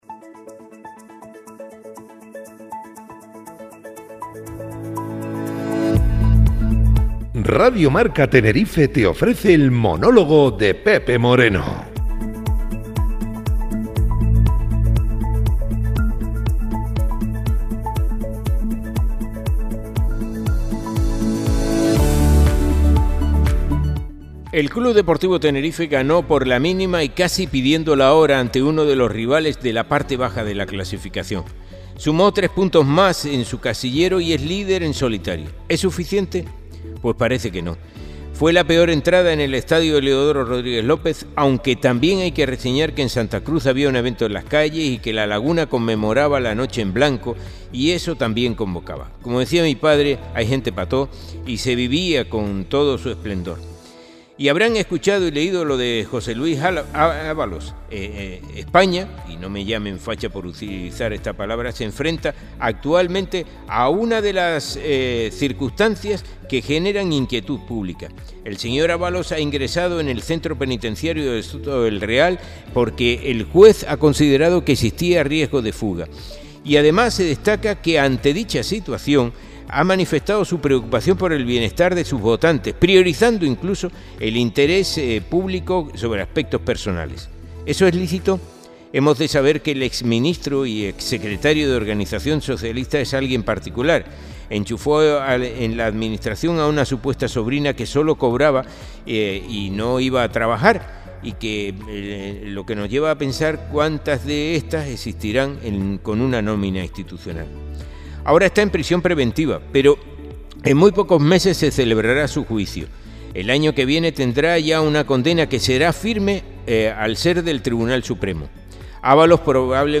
El monólogo